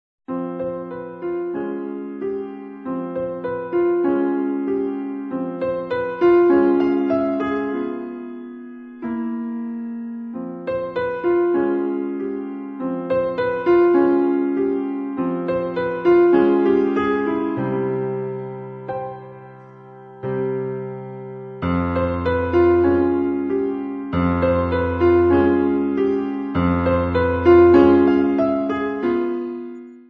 Besetzung: Klavier